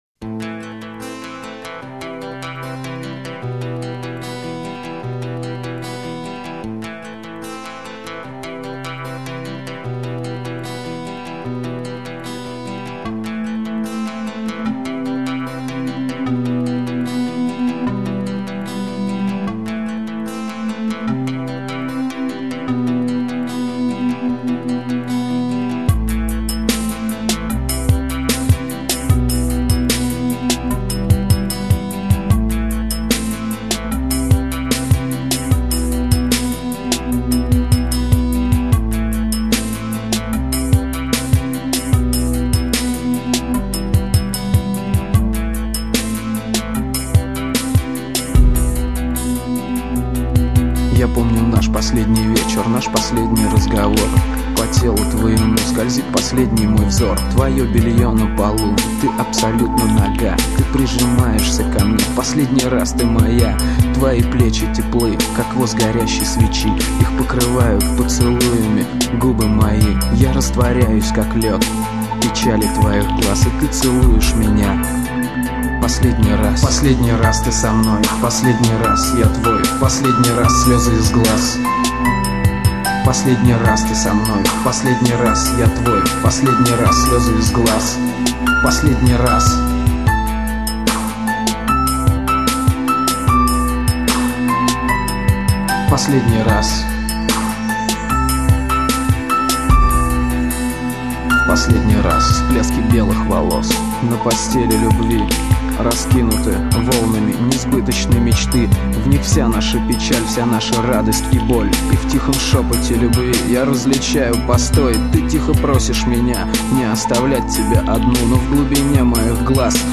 Стиль: Rap